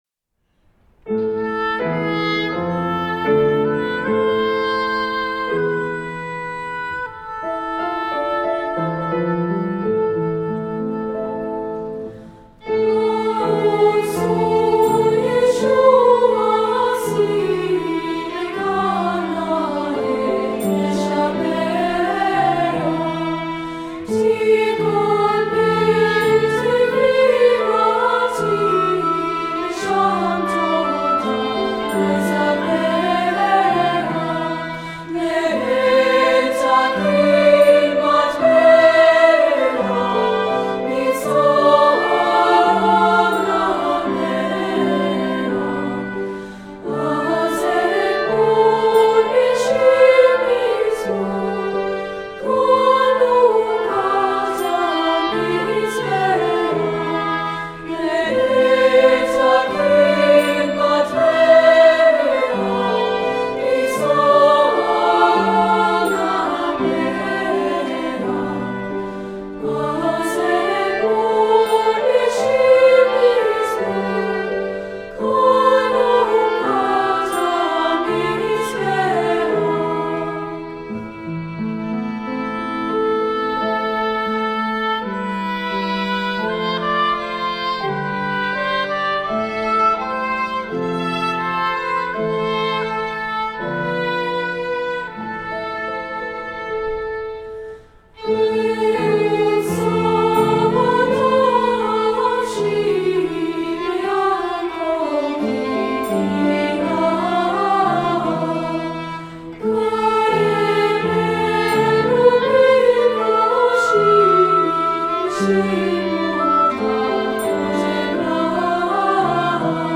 Composer: Traditional Hebrew
Voicing: SA